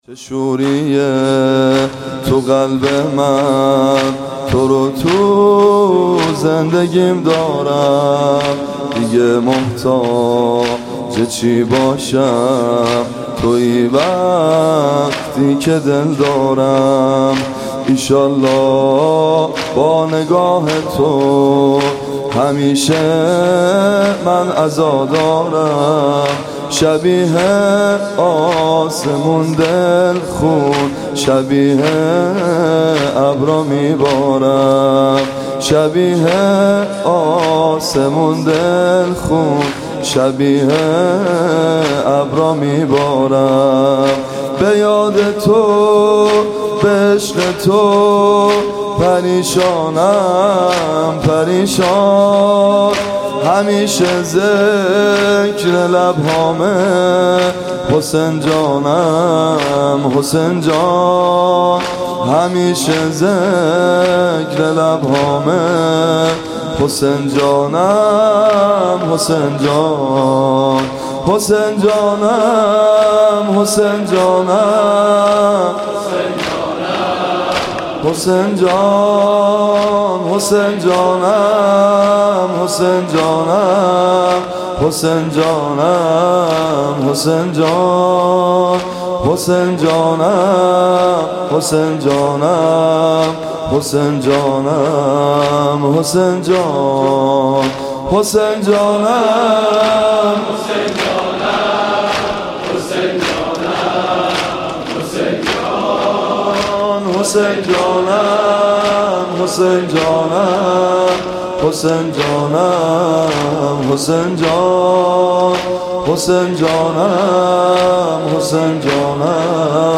صوت مراسم شب هفتم محرم ۱۴۳۷ هیئت ابن الرضا(ع) ذیلاً می‌آید: